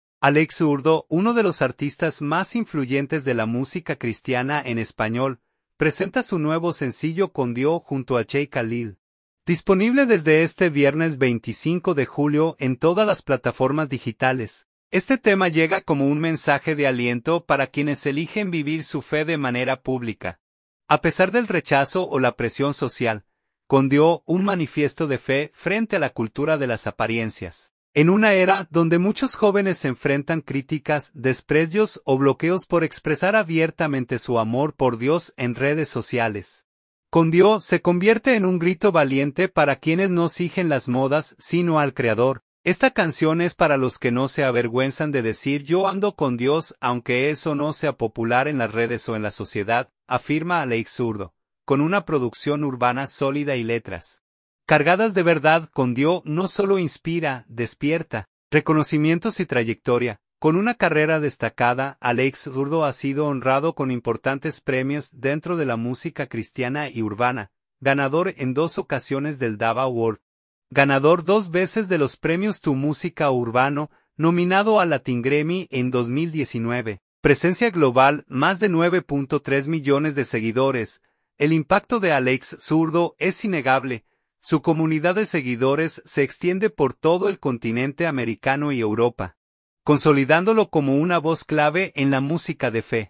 Con una producción urbana sólida y letras cargadas de verdad